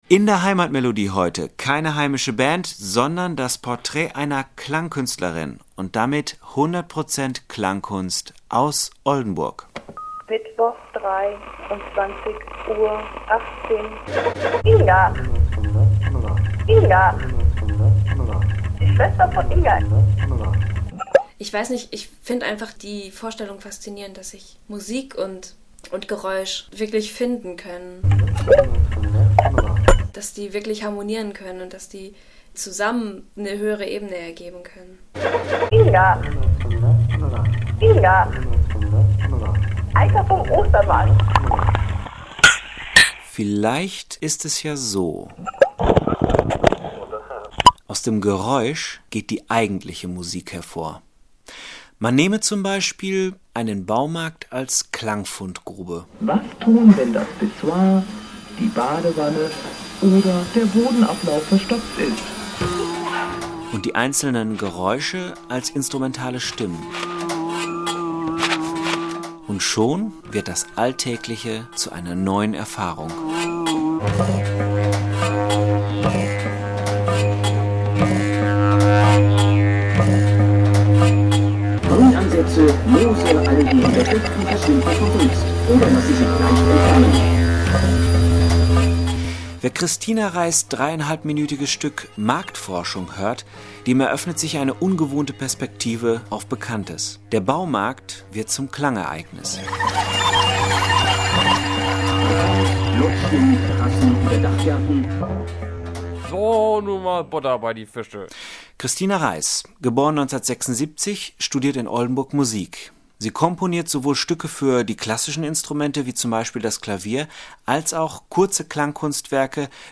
I. Was ist Klangkunst? – Eine einstündige Radiosendung.
Die Grenzen zwischen Musik und Geräusch verschwimmen schnell… und der Hörer erlebt ganz neue und ungewohnte akustische Perspektiven.
Sie wurde am 15.10.2003 und in einer Wiederholung am 16.10. auf O1 gesendet.